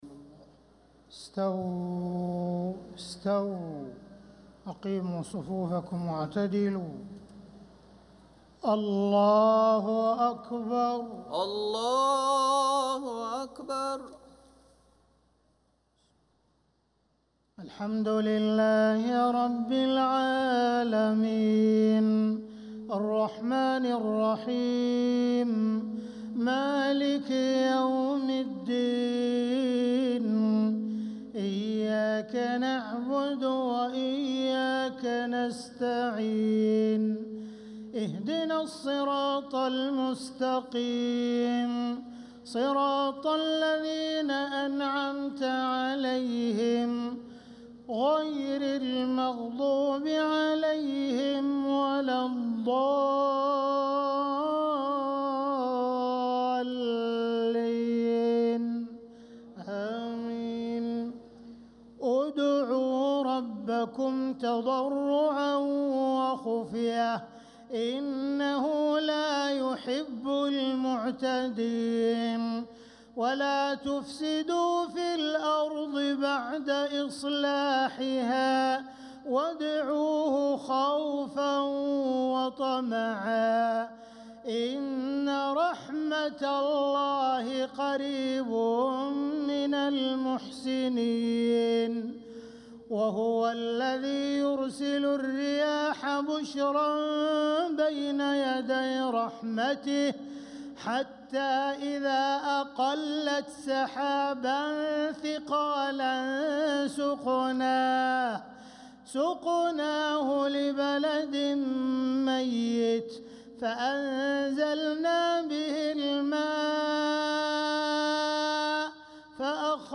صلاة العشاء للقارئ عبدالرحمن السديس 17 صفر 1446 هـ
تِلَاوَات الْحَرَمَيْن .